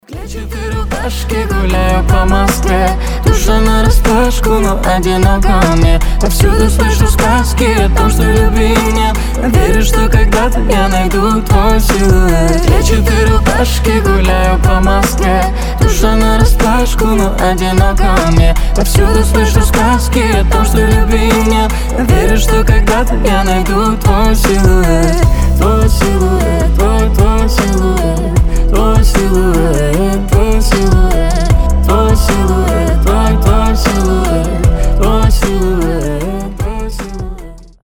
• Качество: 320, Stereo
красивые
дуэт